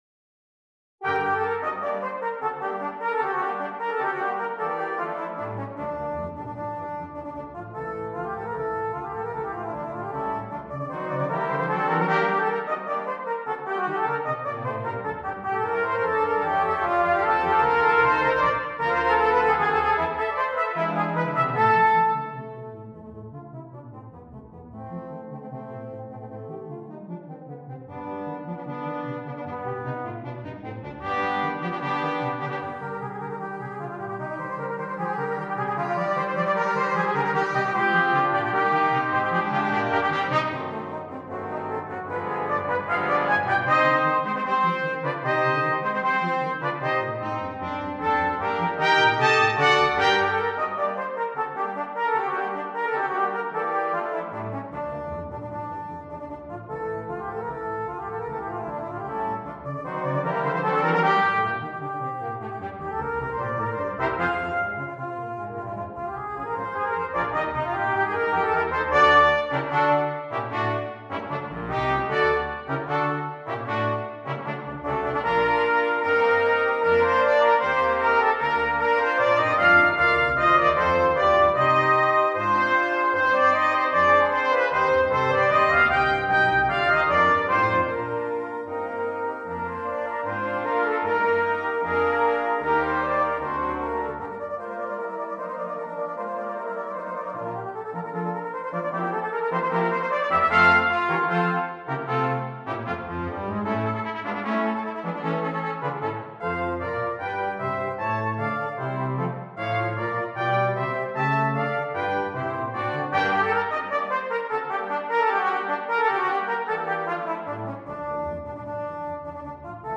Brass Quintet and Solo Trumpet